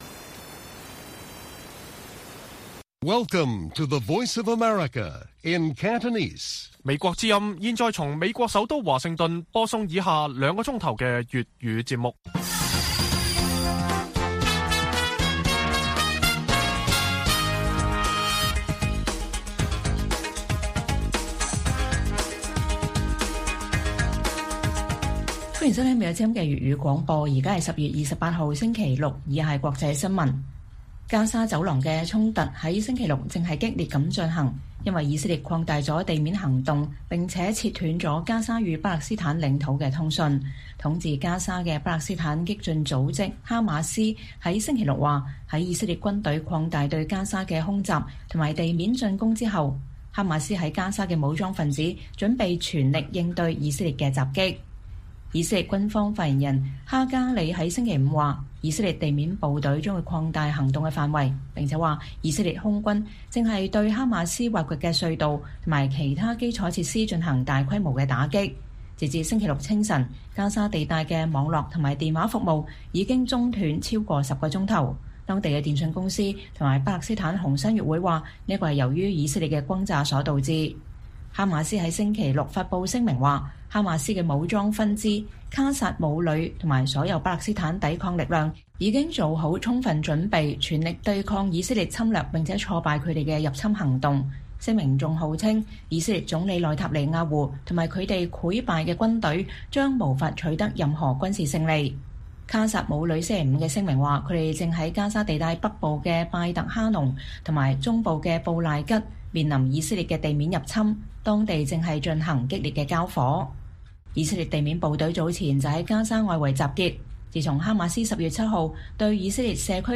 粵語新聞 晚上9-10點: 以色列對加沙加強地面行動後 哈馬斯誓言“全力反擊”